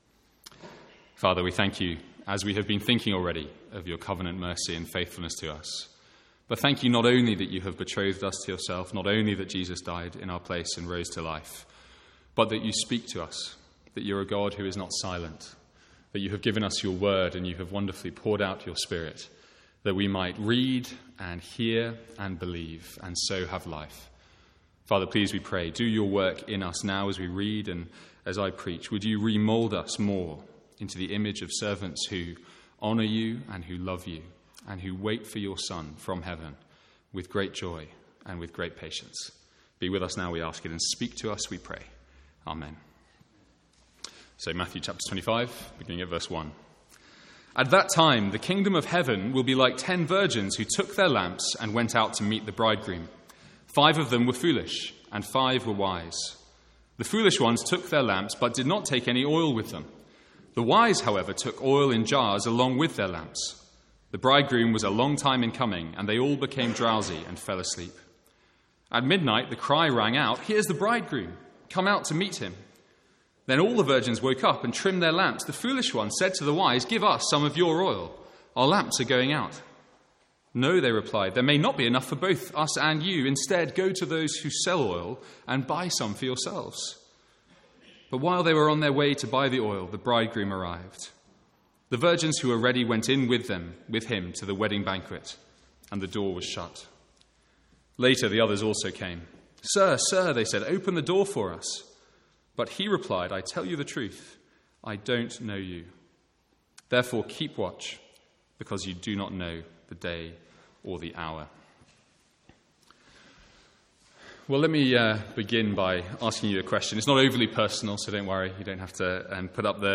Sermons | St Andrews Free Church
From the Sunday morning series in Matthew's gospel.